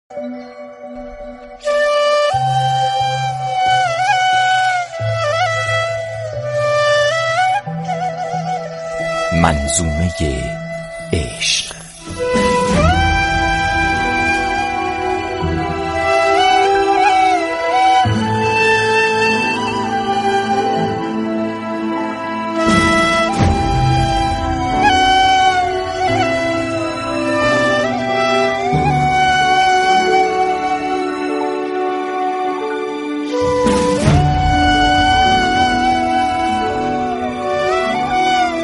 با بیان و لحن آرام